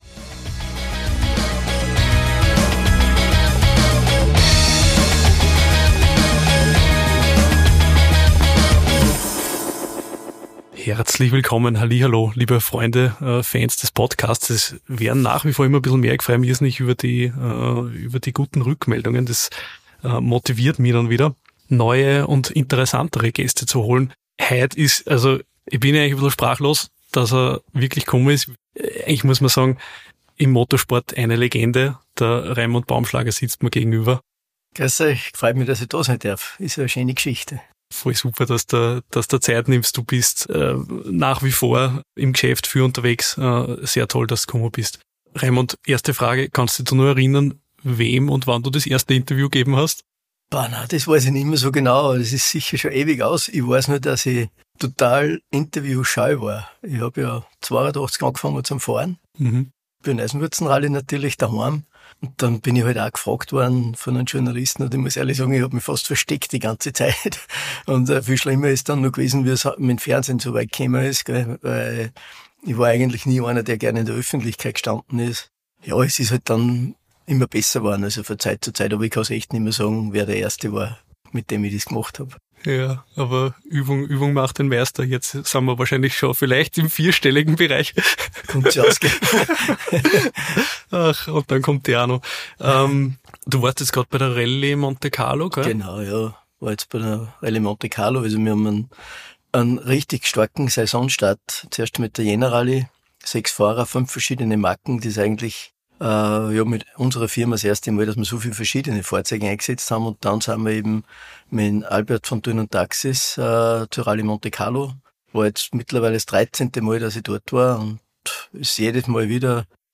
Rallye-Staatsmeister Raimund Baumschlager im Gespräch